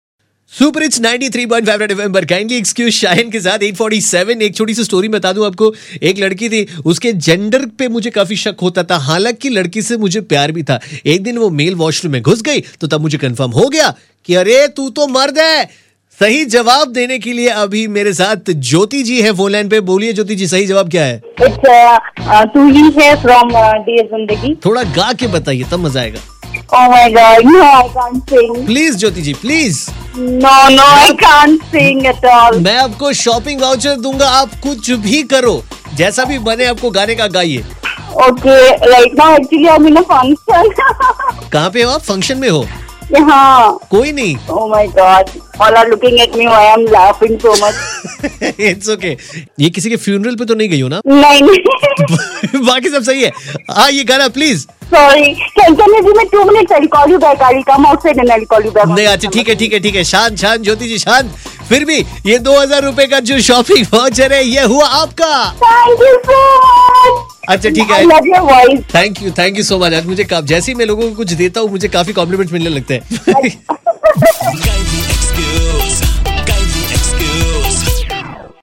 Red FM's twisted song contest with probably the most shy caller ever!